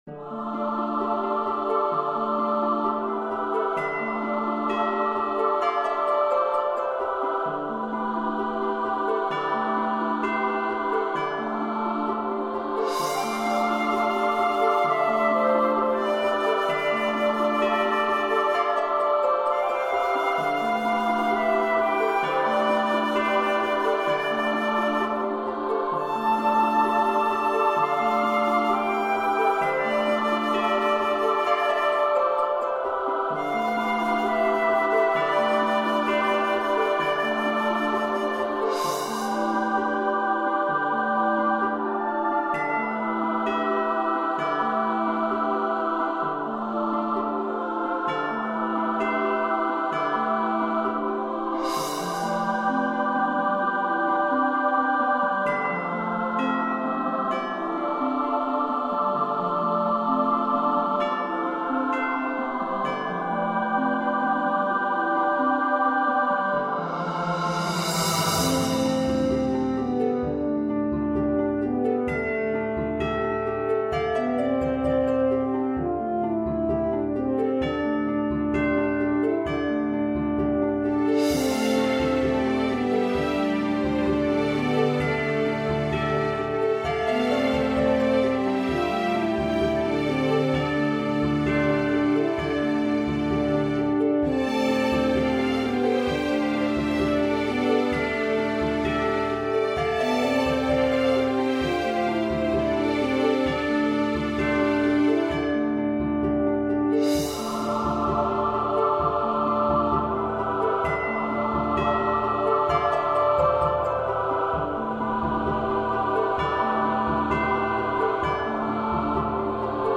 RPG-like music